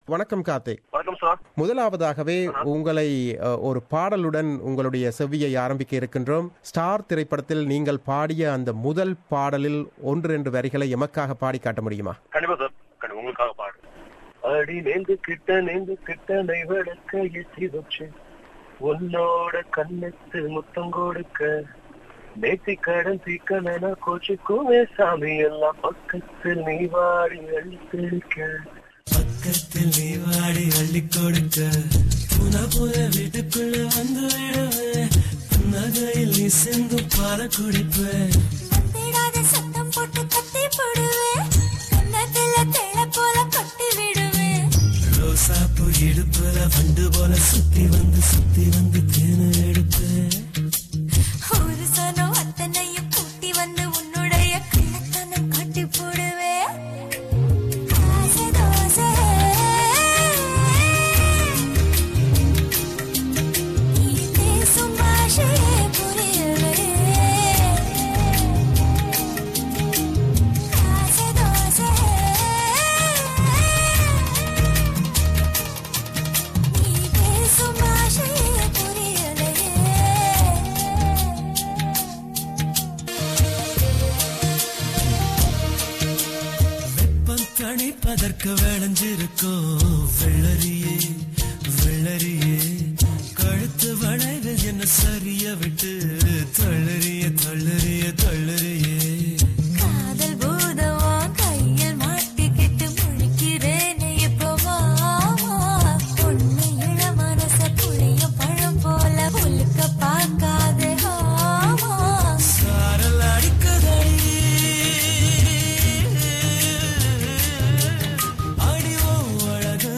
Interview with The Super Star Singer Karthik - Part 1